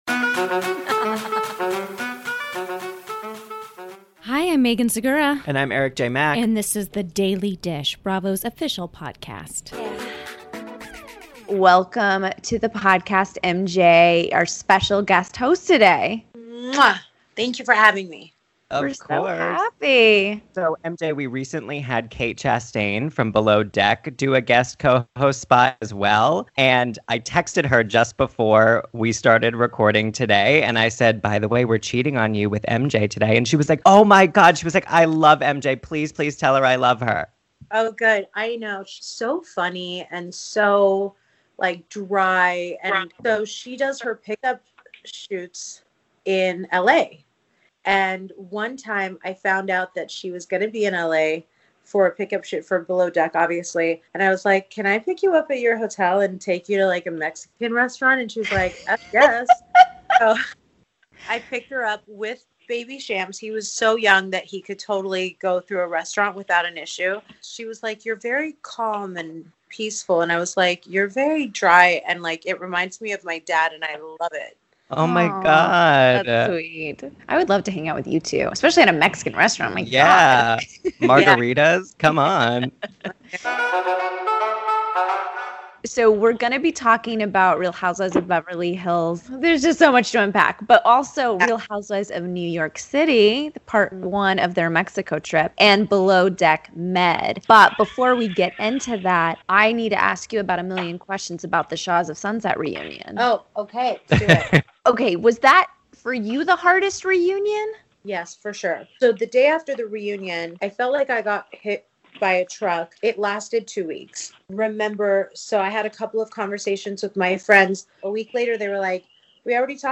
Shahs of Sunset star MJ Javid joined us as a guest co-host this week, fresh off the emotional Shahs of Sunset reunion.
Plus, Below Deck Med Second Stew Bugsy Drake calls in to explain what it was like working with a flustered Chef Kiko in the galley, and how she navigated working for Hannah Ferrier for a second time.